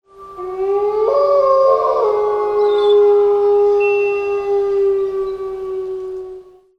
wolf-howl-1.mp3